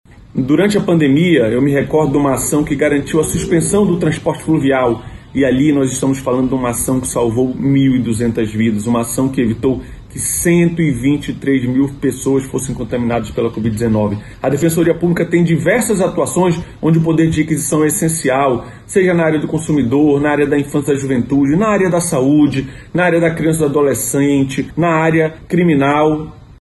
Sonora-1-Ricardo-Paiva-–-defensor-publico-geral-do-Amazonas.mp3